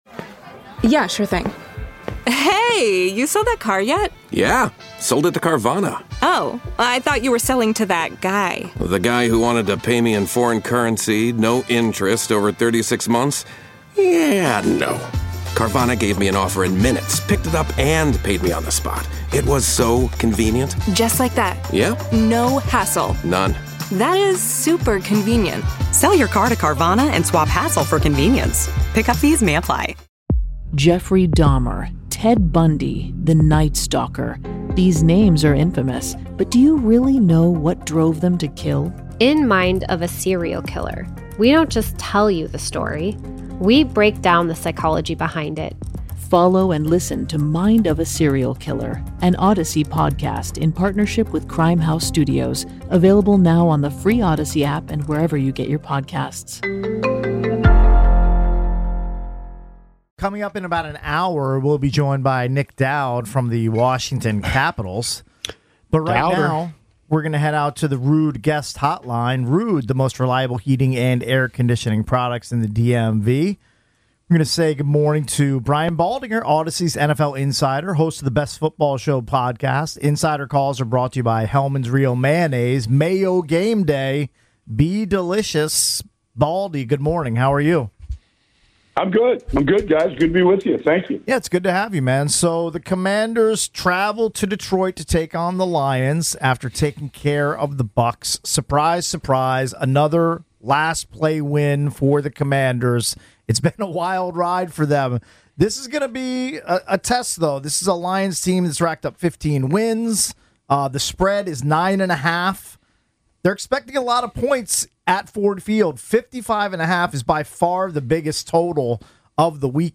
For more than 25 years, The Junks have owned Washington D.C. sports radio, covering Commanders, Nationals, Capitals, Wizards, Hokies, Terrapins, and Hoyas news.